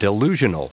Prononciation du mot delusional en anglais (fichier audio)
Prononciation du mot : delusional